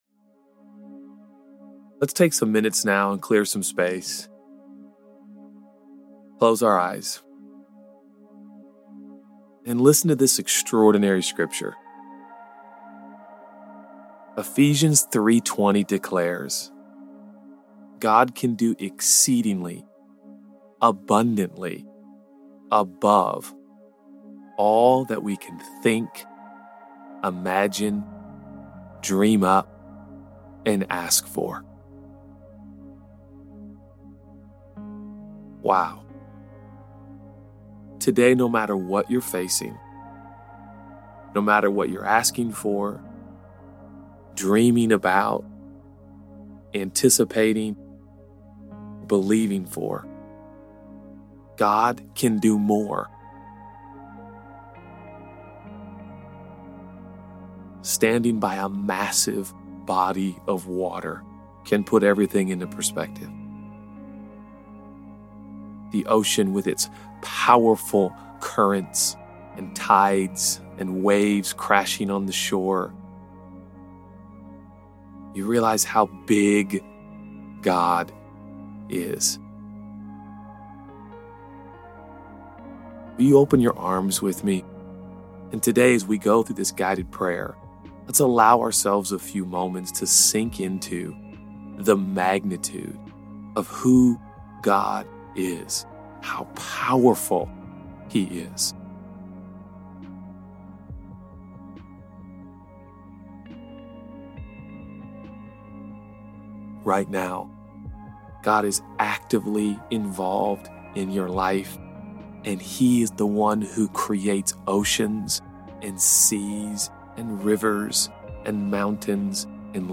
God can do more than we ask, think, or imagine. In this guided prayer, pause to reflect on his power, give thanks for what he is already working out in your life, and pray for someone who needs a miracle today.